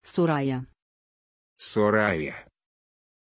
Pronunciation of Sorayah
pronunciation-sorayah-ru.mp3